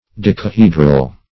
Decahedral \Dec`a*he"dral\ (d[e^]k`[.a]*h[=e]"dral)
decahedral.mp3